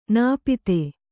♪ nāpite